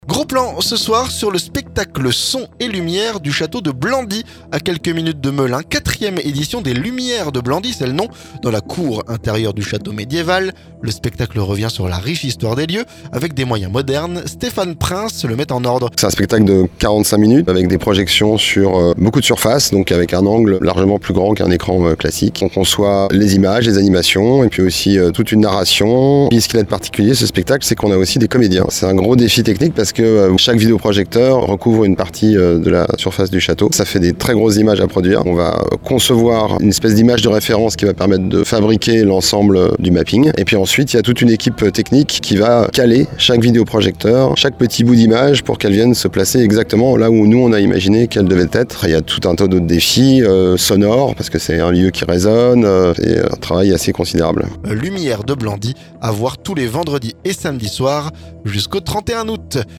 *Reportage